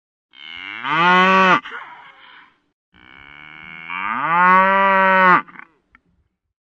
/64kbps) Описание: Мычание коров.
Korova.mp3